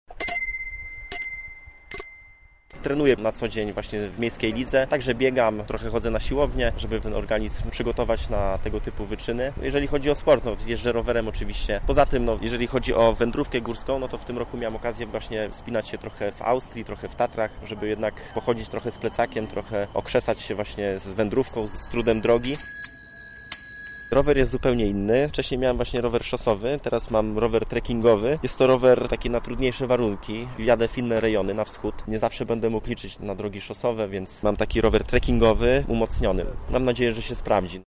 Na starcie wyprawy była nasza reporterka.